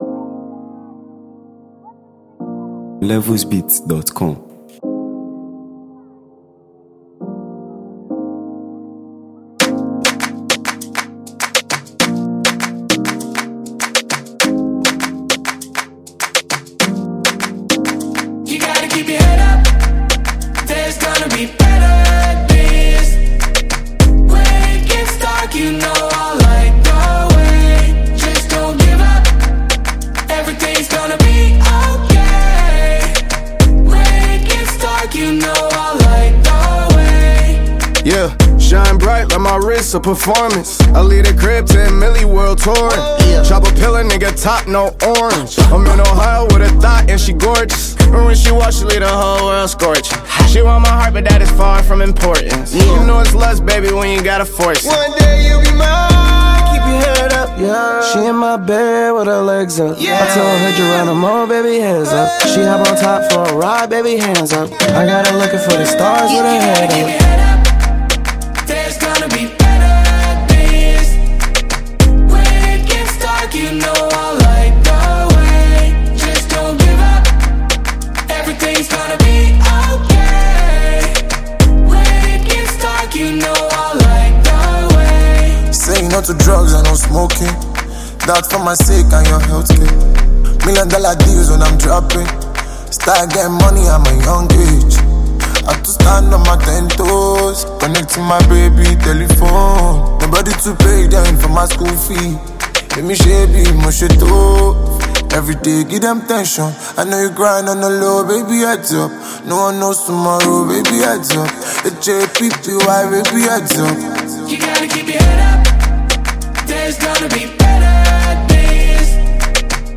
From its captivating hook to its dynamic production